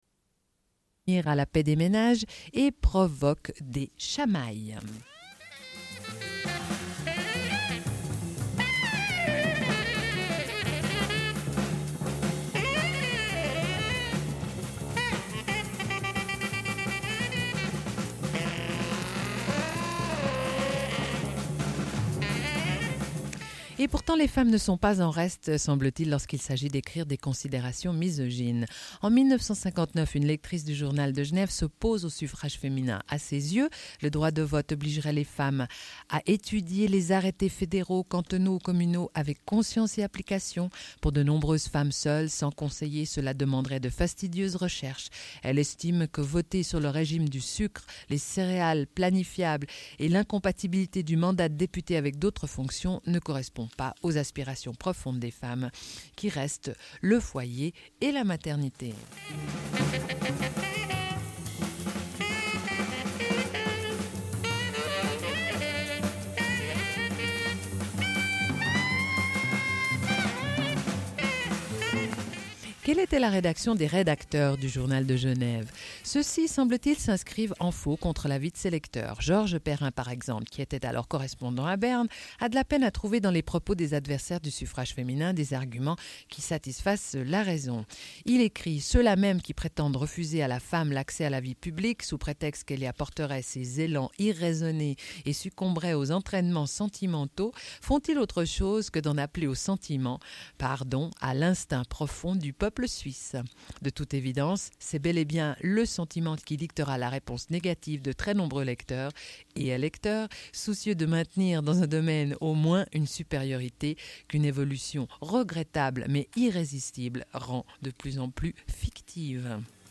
Suite de l'émission : au sujet du droit de vote des femmes en Suisse, obtenu le 7 février 1971, à l'occasion du 25ème. Revue de presse (Femmes suisses, Le Courrier, Tribune de Genève, Journal de Genève...).
Une cassette audio, face B
Radio Enregistrement sonore